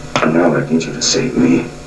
THE VOICE OF LANCE HENRIKSEN